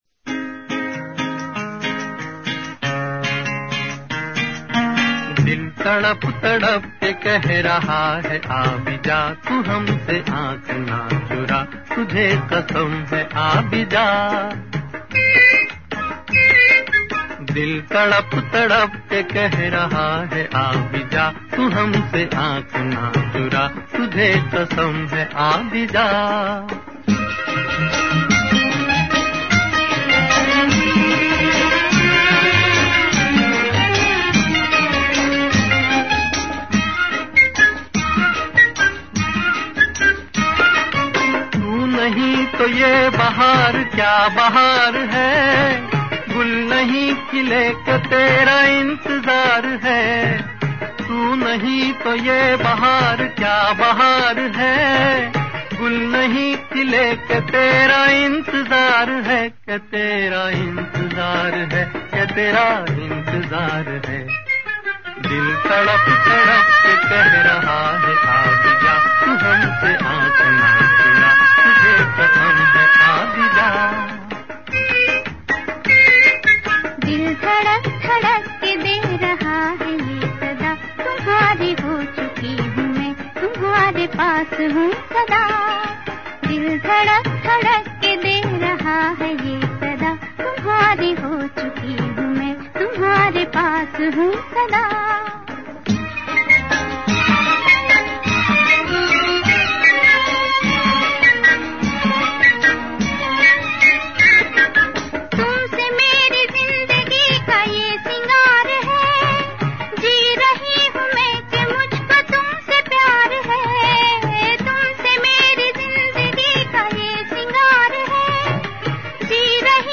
a true celebration of folk music with amazing orchestration